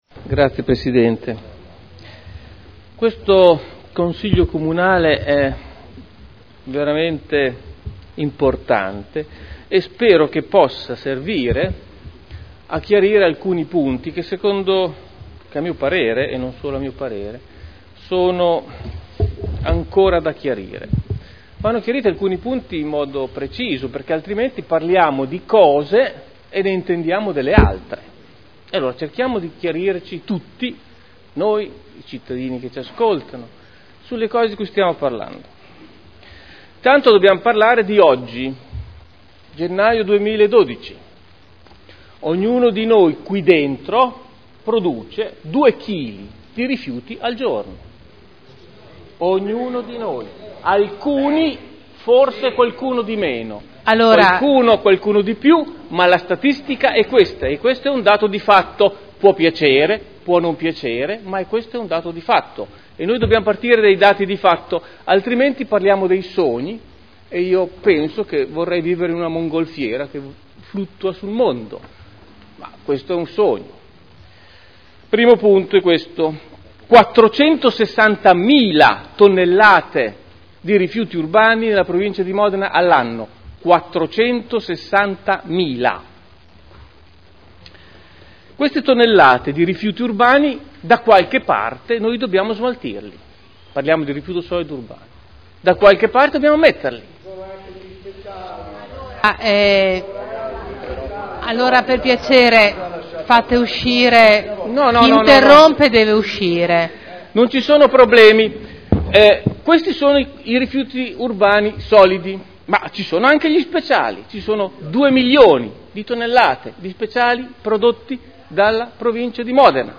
Luigi Alberto Pini — Sito Audio Consiglio Comunale